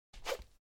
Download Swish sound effect for free.
Swish